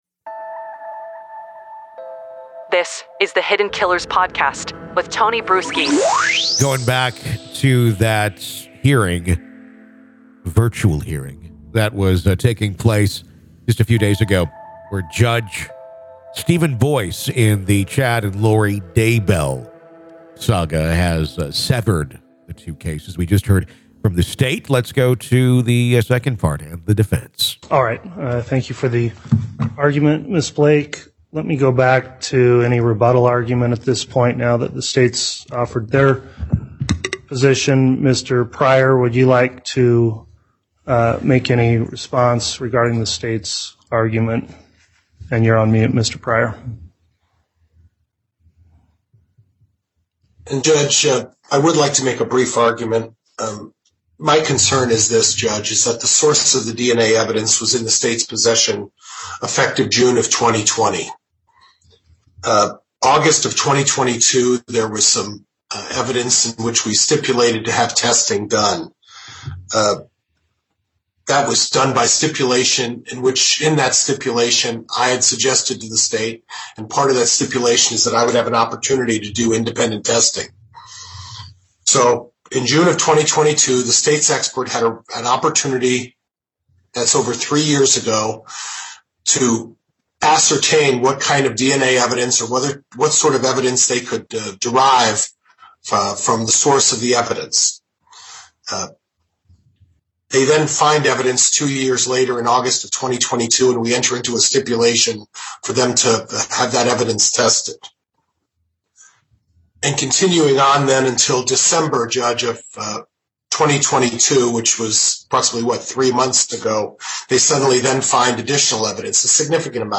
Hear Judge Boyce's Ruling: Lori Vallow and Chad Daybell to Be Tried Separately